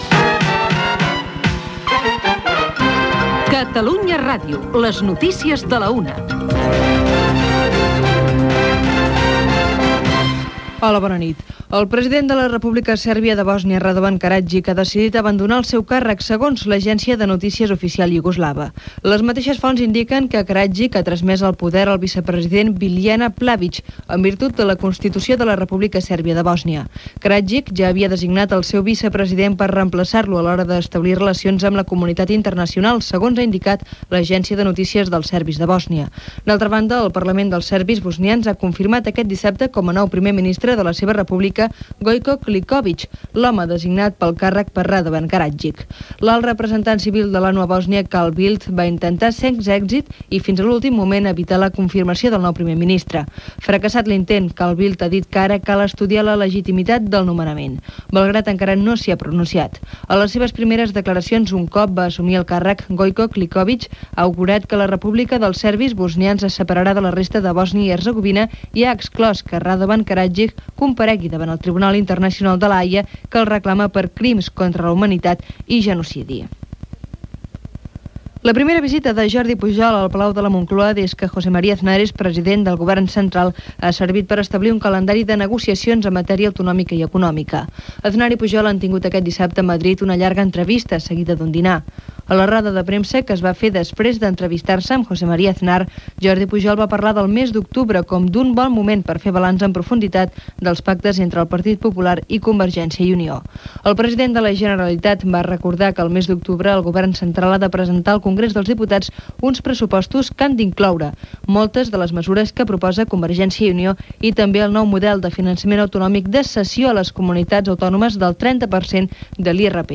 Careta d'entrada.
Careta de sortida.
Indicatiu de l'emissora.
Informatiu
FM